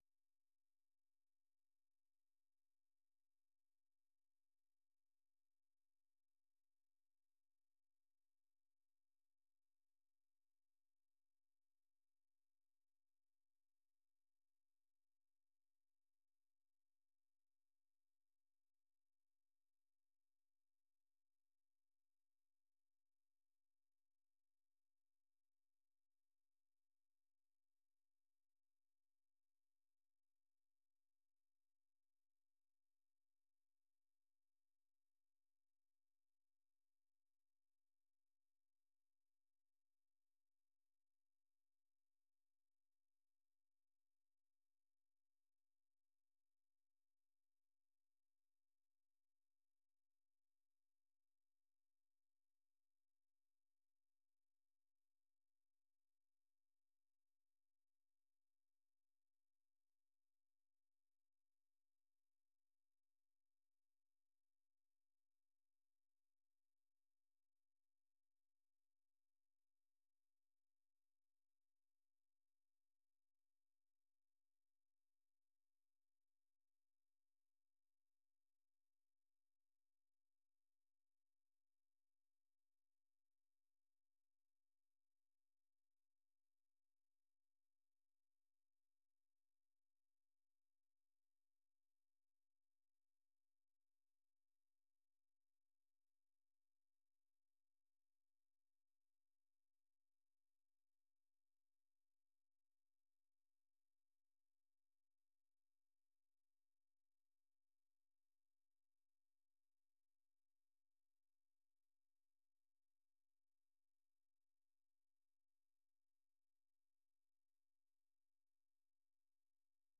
- Les orchestres gigantesques et les groups qui ont grandement contribué a changer les mœurs et société, ce classement comprend divers genre musicaux (Rap, Rock, Pop, R&b etc.) afin de satisfaire le plus grand nombre.